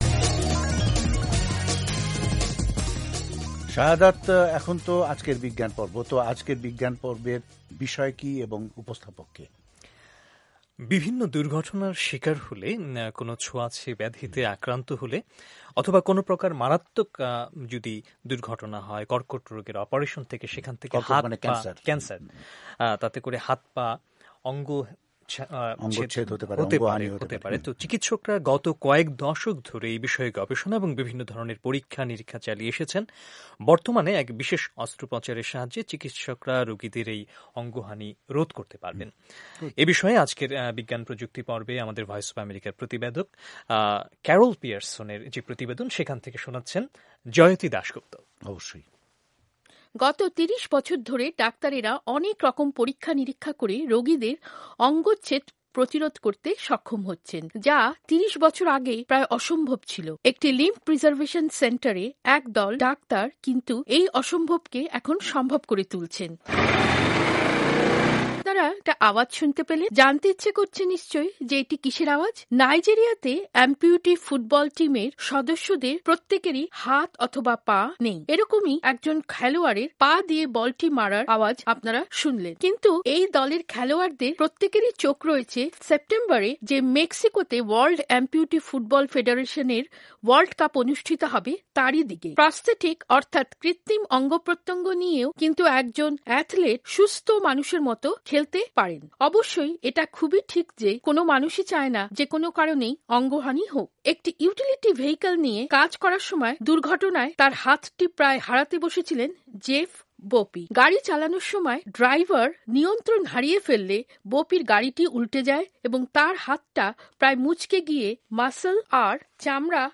প্রতিবেদনটির বাংলা রুপান্তর পড়ে শোনাচ্ছেন